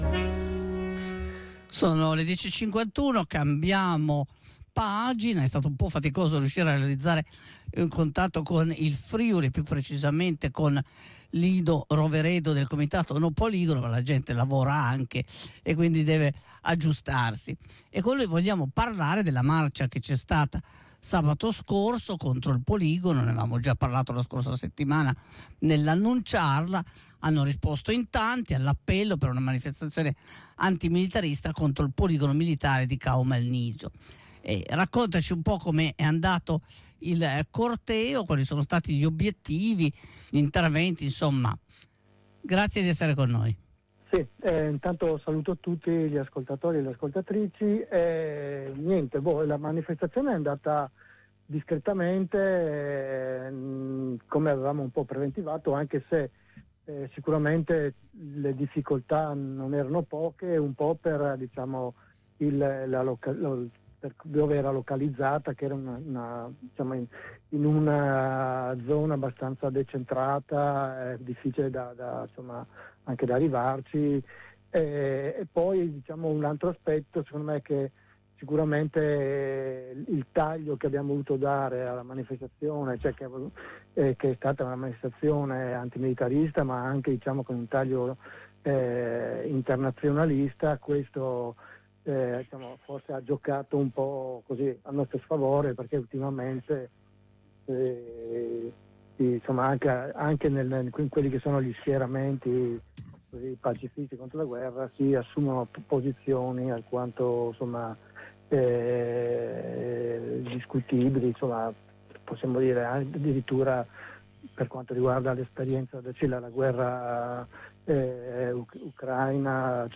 Ascolta la diretta: